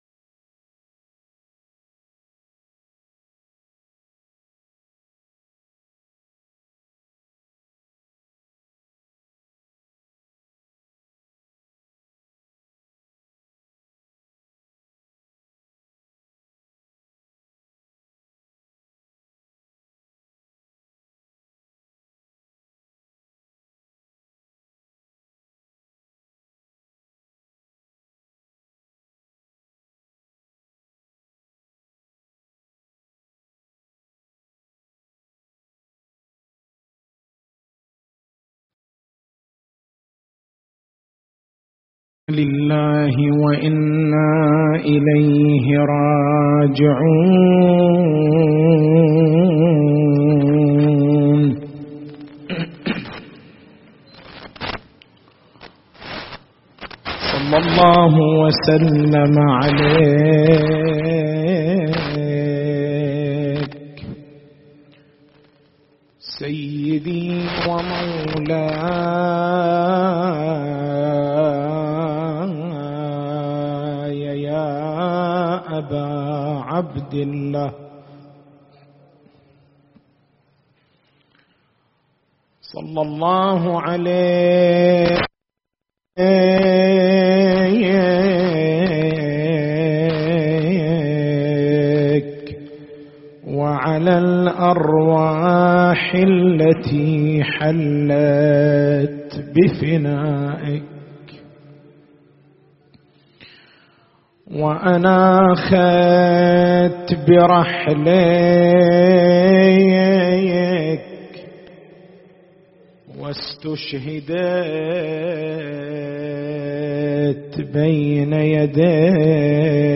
حسينية بن جمعة بالكويكب